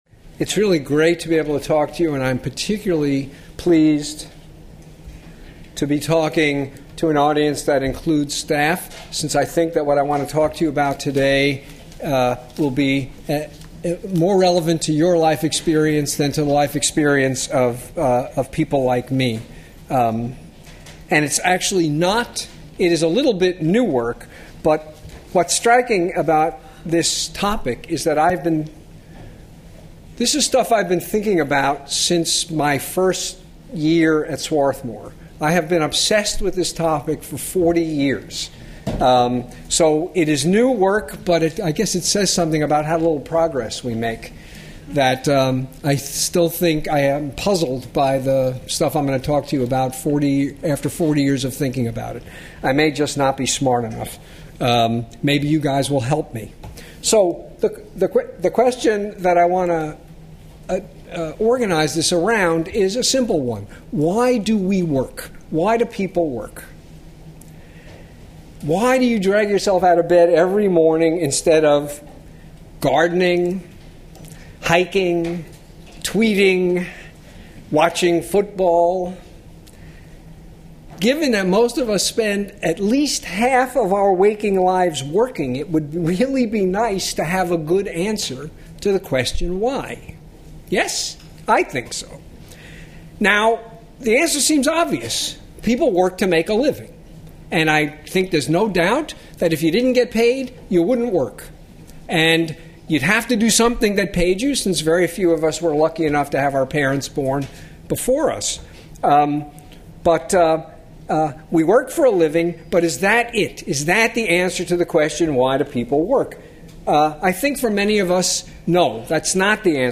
Schwartz, who explores the social and psychological effects of free-market economic institutions on moral, social, and civic concerns, discusses intrinsic and extrinsic motivation as part of the Second Tuesday Social Sciences Cafe lecture series.